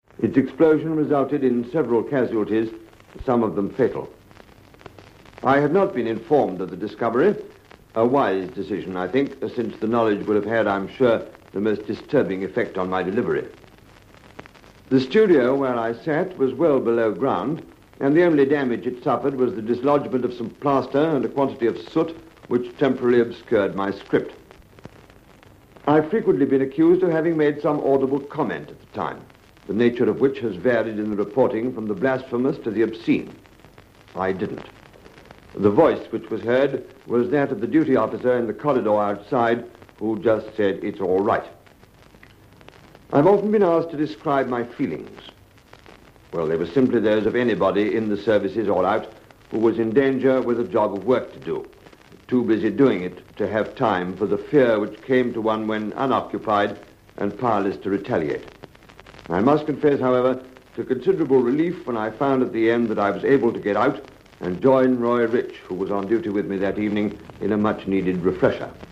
Here, Bruce tells his story.